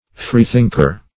Freethinker \Free"think`er\, n.